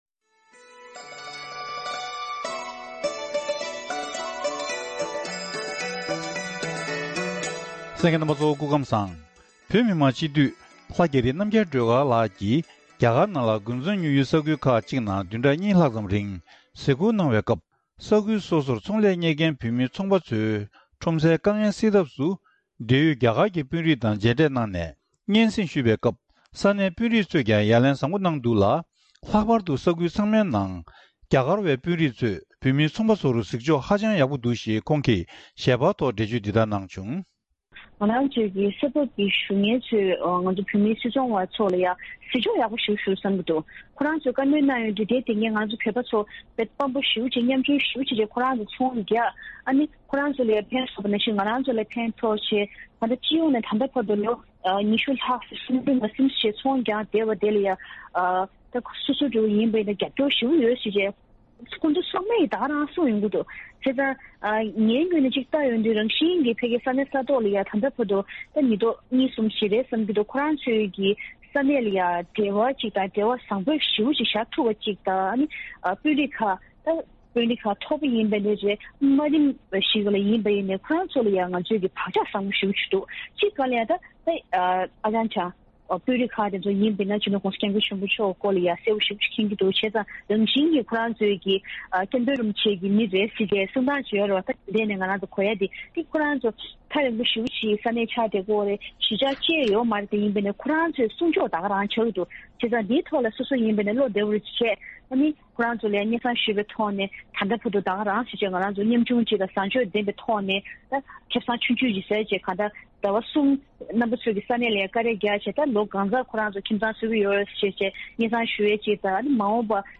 ཁོང་ལ་བཀའ་འདྲི་ཞུས་ནས་ཕྱོགས་སྒྲིགས་ཞུས་ པ་ཞིག་གསན་རོགས་གནང་།